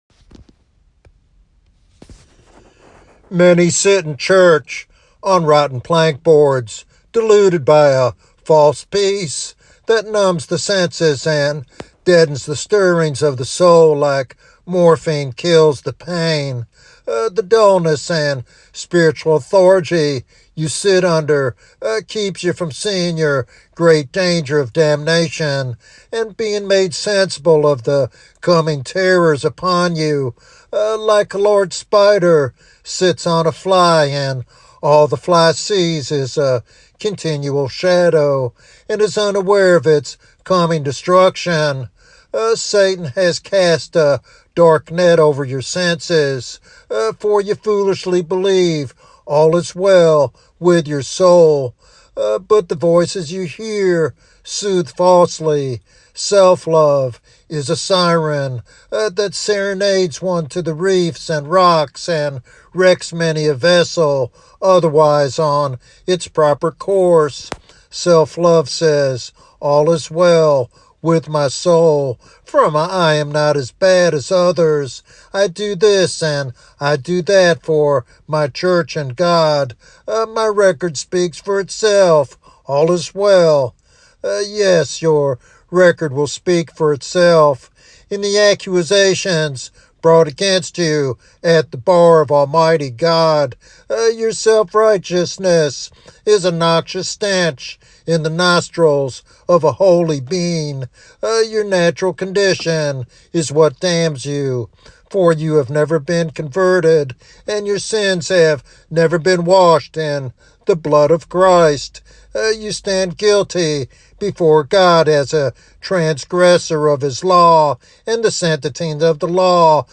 This sermon challenges the complacent and offers hope through the gospel of salvation.